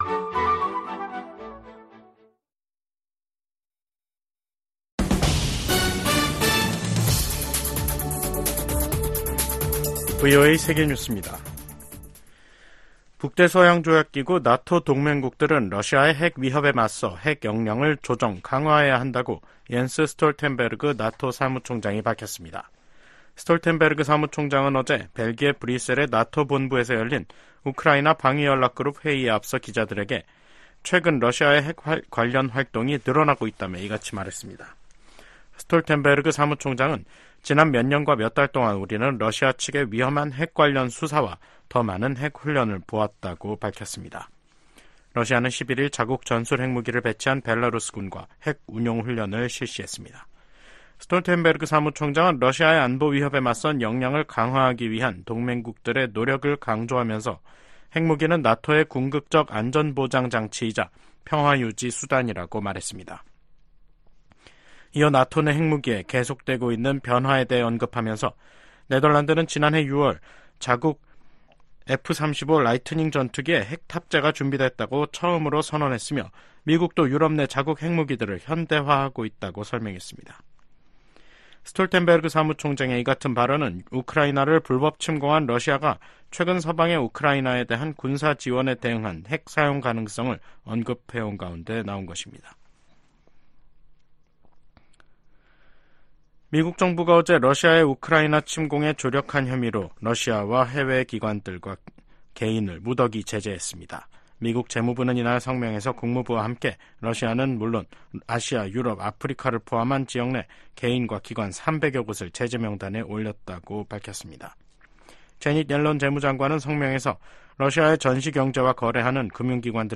VOA 한국어 간판 뉴스 프로그램 '뉴스 투데이', 2024년 6월 13일 2부 방송입니다. 유엔 안보리에서 열린 북한 인권공개 회의에서 미국과 한국, 일본 등은 북한 인권 유린이 불법적인 무기 개발과 밀접한 관계에 있다고 지적했습니다. 미한일 등 50여개국과 유럽연합(EU)이 뉴욕 유엔본부에서 개선될 조짐이 없는 북한의 인권 상황에 대해 우려를 표명했습니다.